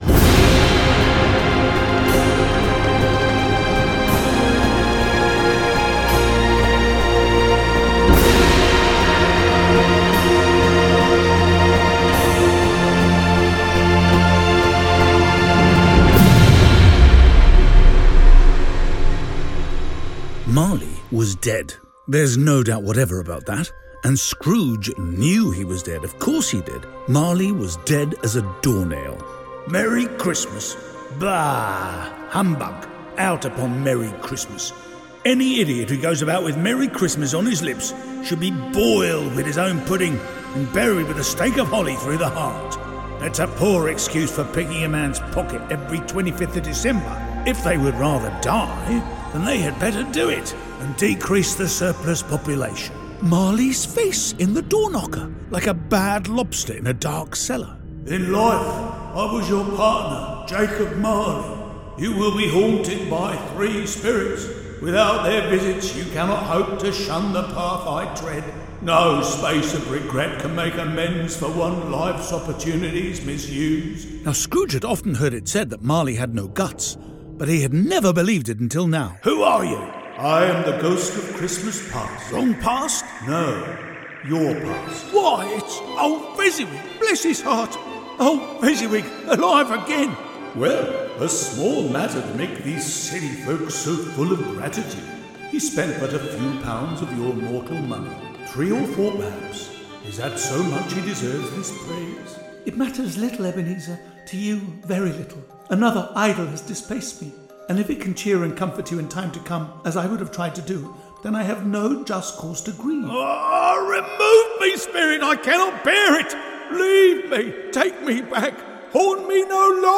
It's all done with cross-fading lights and variations of voice; and, in the case of the four spirits, with a resounding echo box.